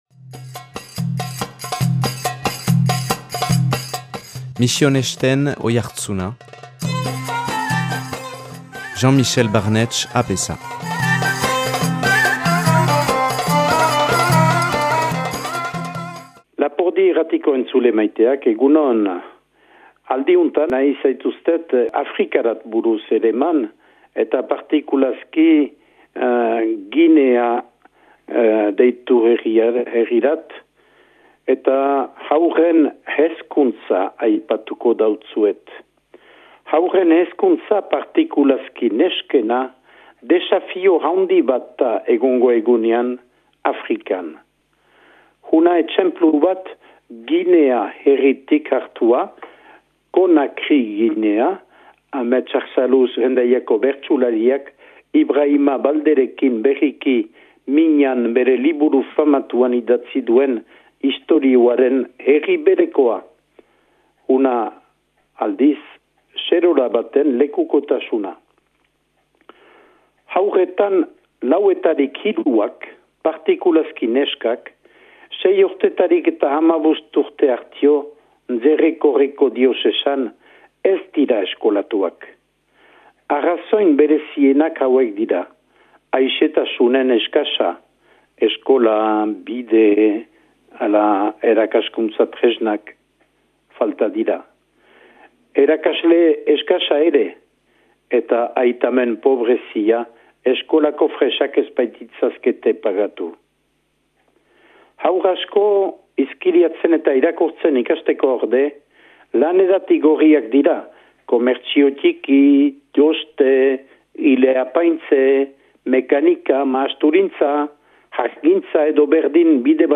Présentateur(trice)